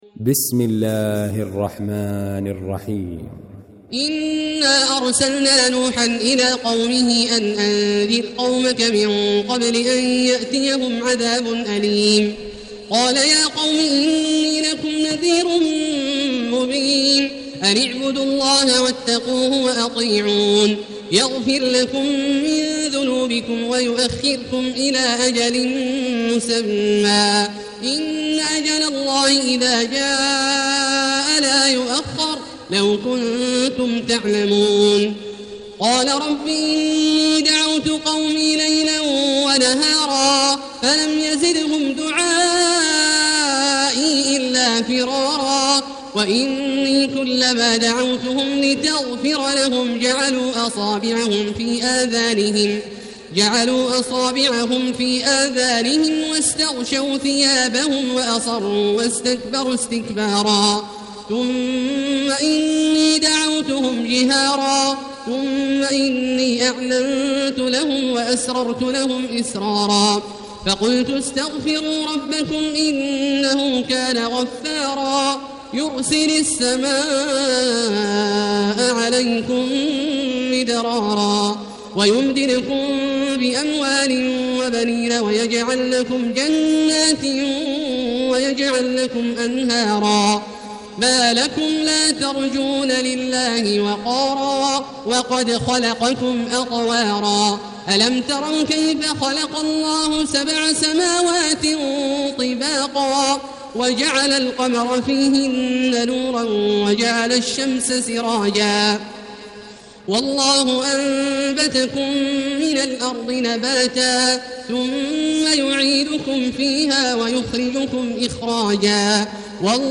المكان: المسجد الحرام الشيخ: فضيلة الشيخ عبدالله الجهني فضيلة الشيخ عبدالله الجهني نوح